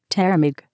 Das Sprachfile habe ich auch mit der AT&T Seite neu erstellt, dort einfach ein bisschen mit "ä" und "h" rumspielen, bis die Aussprache passt.
F_Thermik.wav